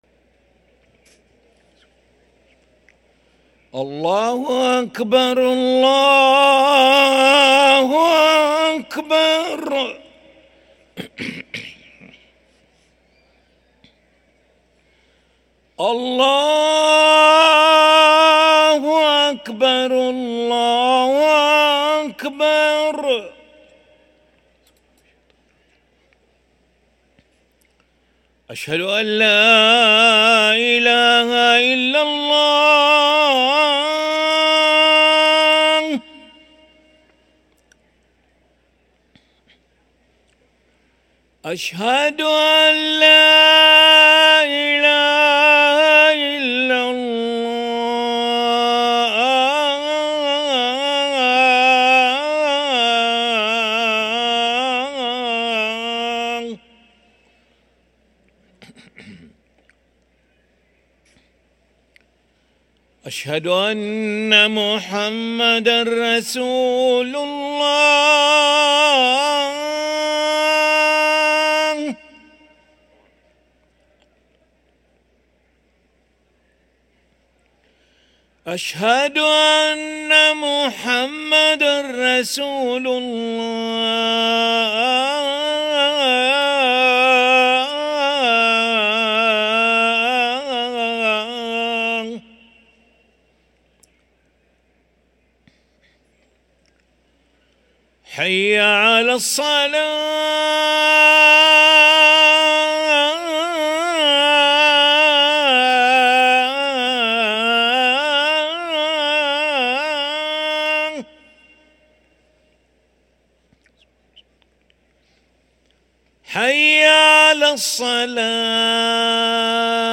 أذان العشاء للمؤذن علي ملا الخميس 8 صفر 1445هـ > ١٤٤٥ 🕋 > ركن الأذان 🕋 > المزيد - تلاوات الحرمين